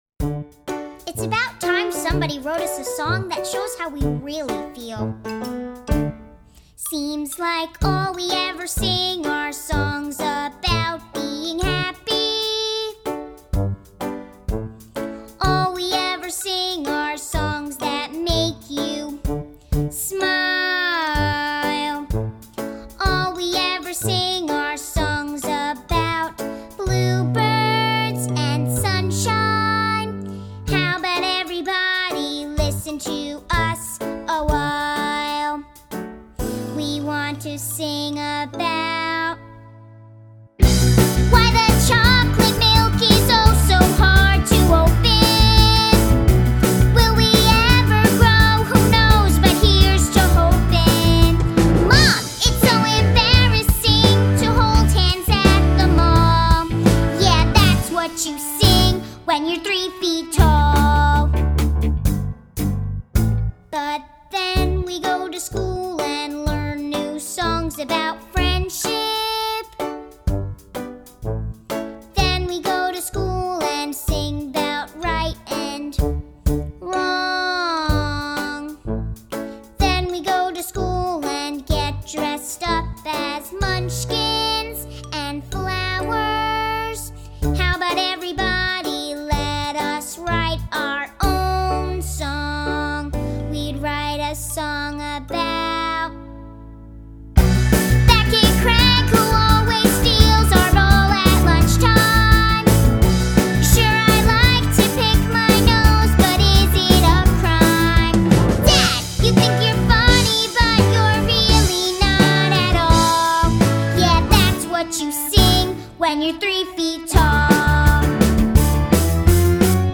This is a silly song, so don’t be afraid to ham it up!
Three-Feet-Tall-VOCAL-1.mp3